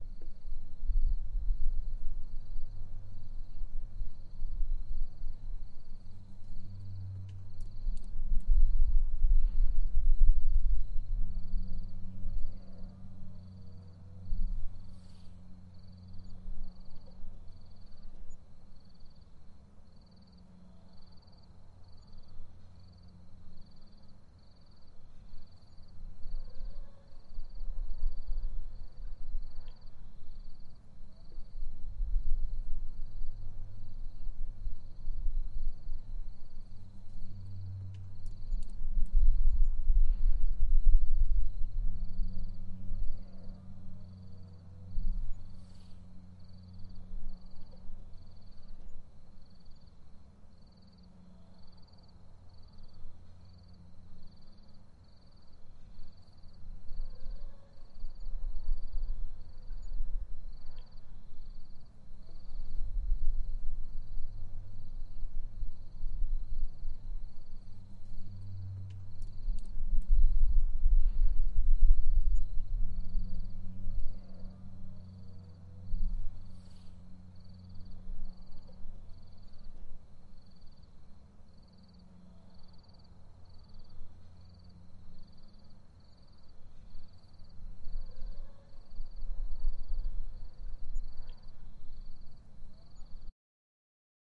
描述：在我们走廊窗外的树枝上录制了一只松鸫的歌唱。
Tag: 现场录音 歌鸫 鸟的歌声